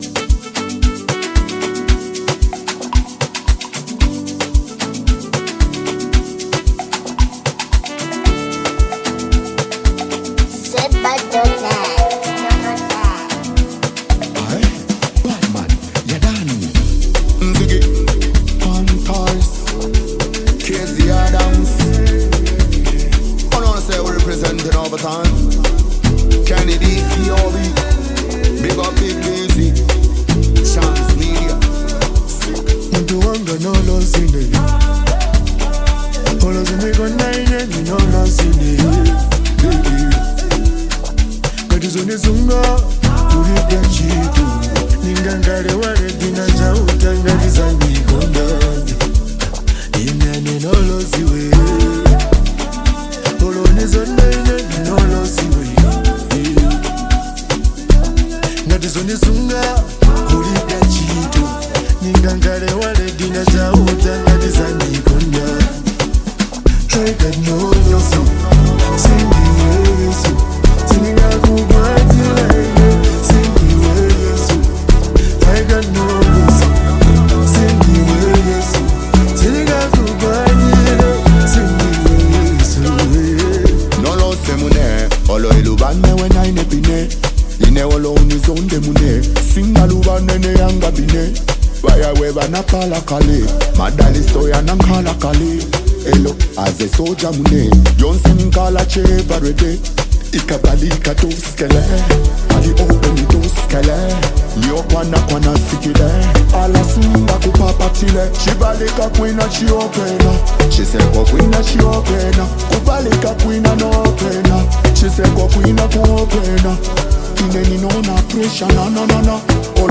Afrobeats Artist • Lusaka, Zambia